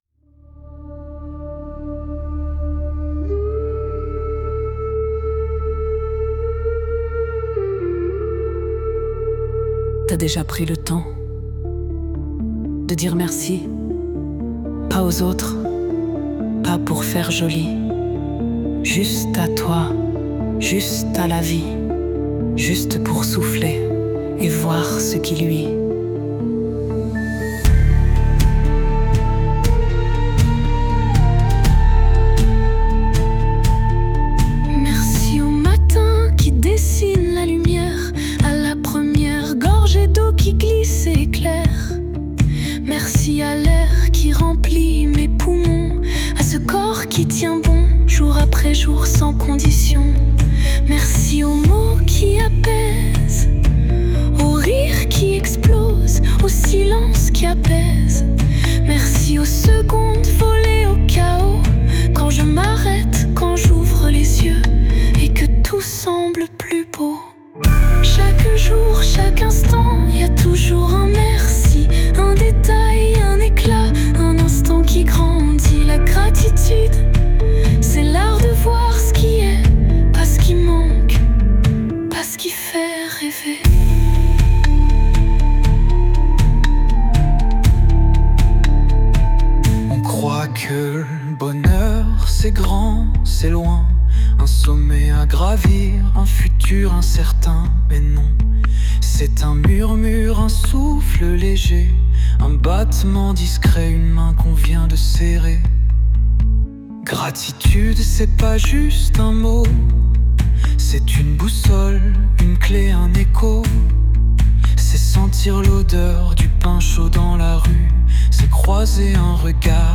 La route vers un esprit plus serein et une vie plus joyeuse commence ici, avec l’écoute de cette magnifique chanson créée spécialement pour vous :